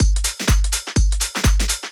House2.wav